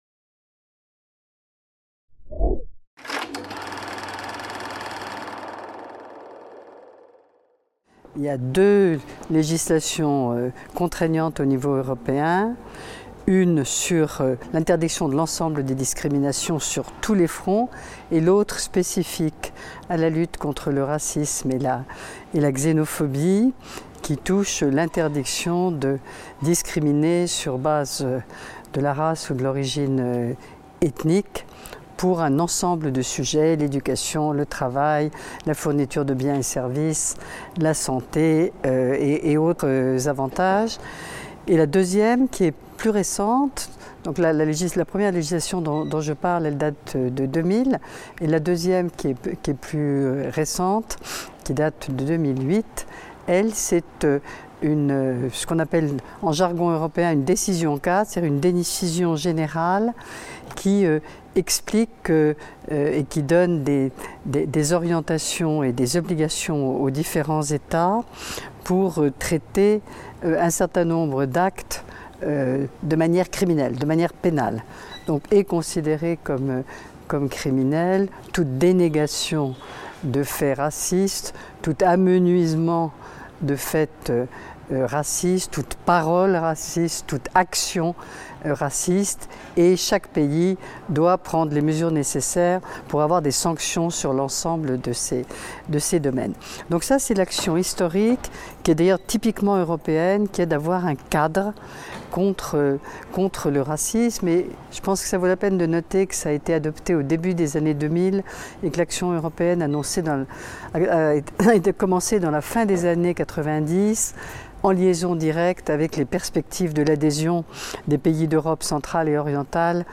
Interview d'Odile Quintin (Pour le lancement du séminaire sur le racisme et l'antisémitisme - PIRA) | Canal U